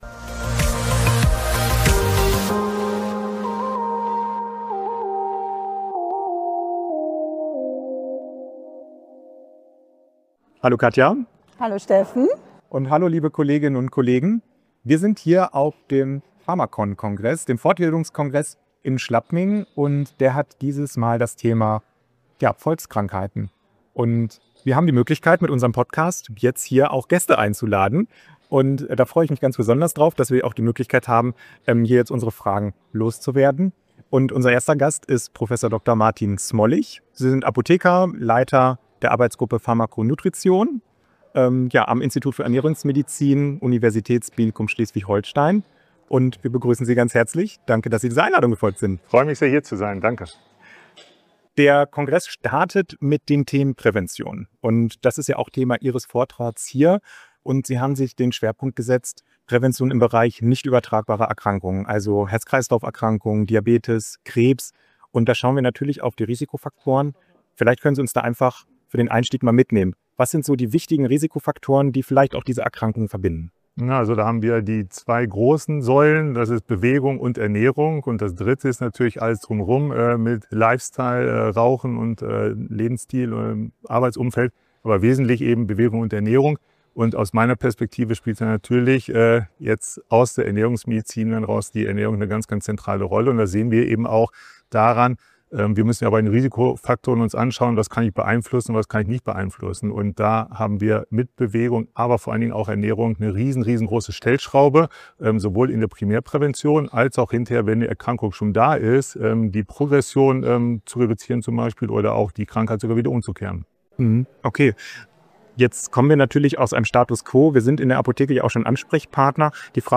Live vom pharmacon